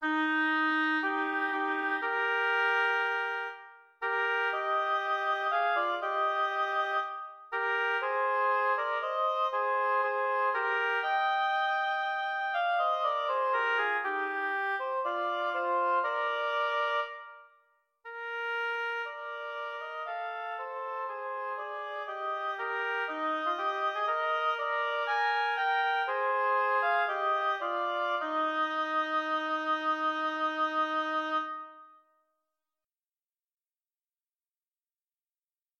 Instrumentation: Oboe duet
tags: oboe music